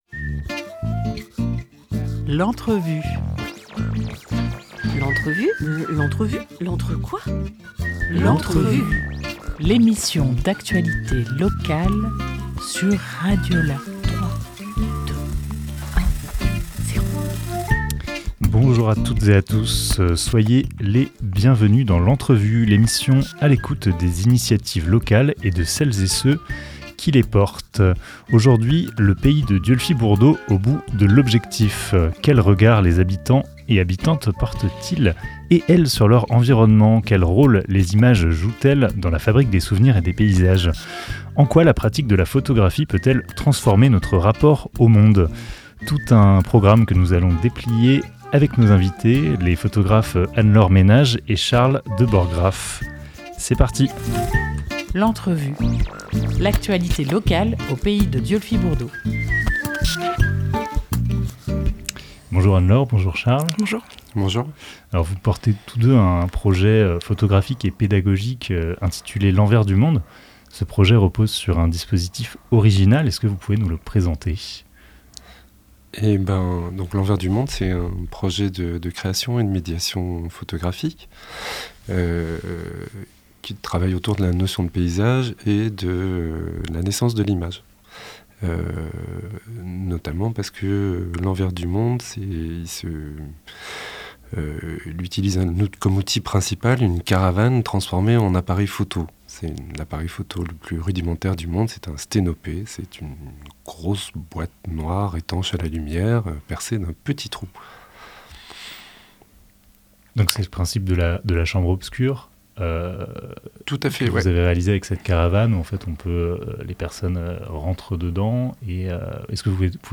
15 février 2024 11:24 | Interview